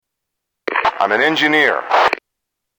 Tags: Games Wolfenstein Enemy Territory Allies Sounds Enemy Territory Video Game